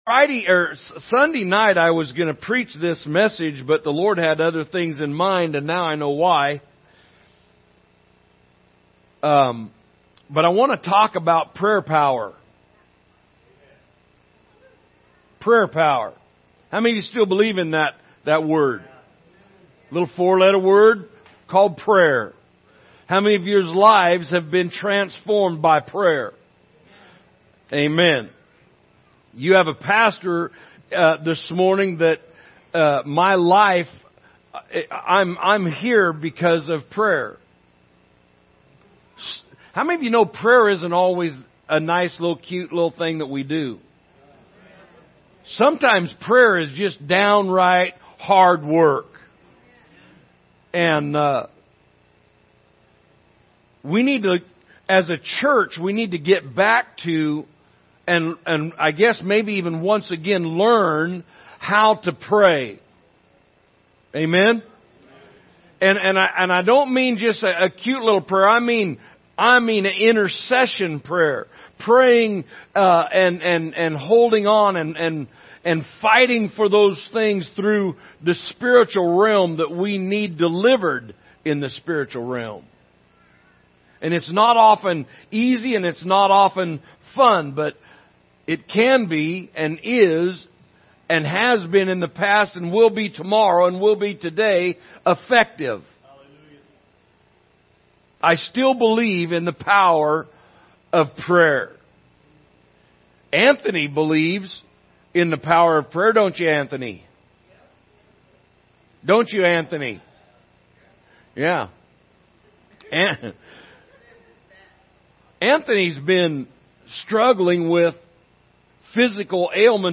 Sunday Evening Service February 12, 2023 – Requirements of Effective Praying
Category: Sermons